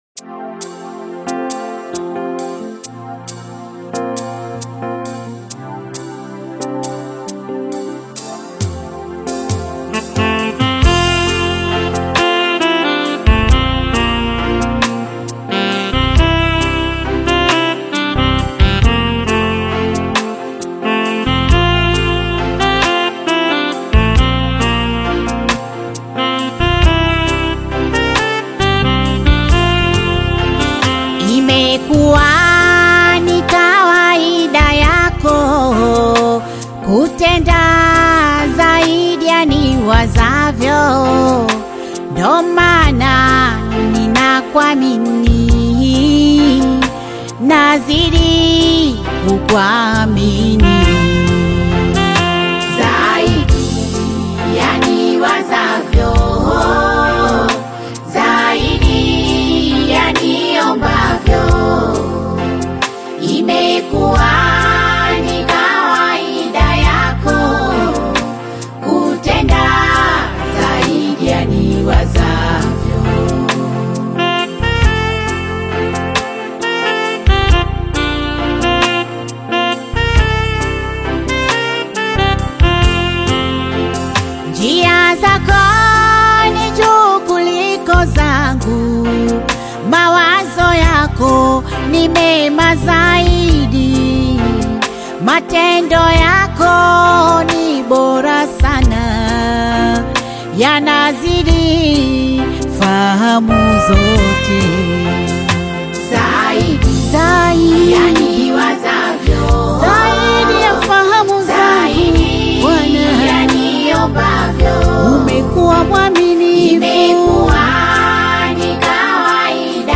gospel single